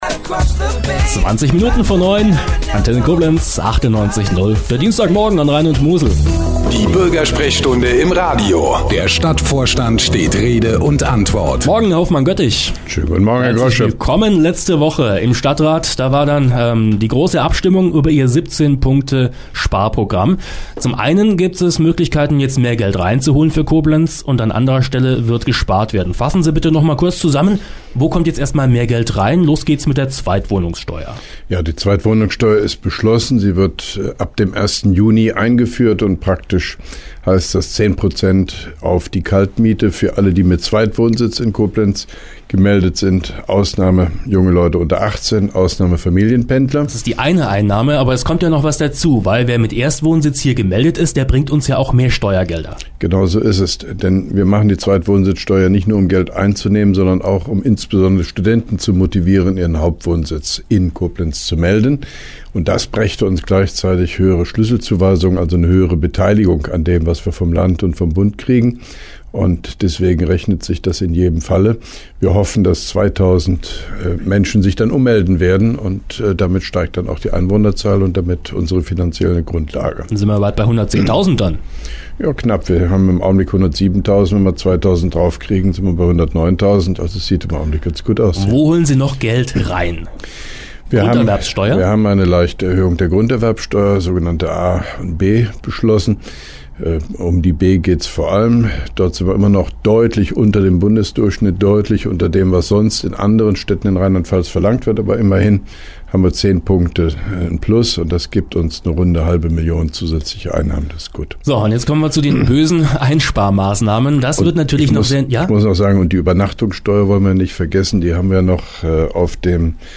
(2) Koblenzer Radio-Bürgersprechstunde mit OB Hofmann-Göttig 07.02.2012
Antenne Koblenz 98,0 am 07.02.2012, ca. 8.40 Uhr (Dauer 06:33 Minuten)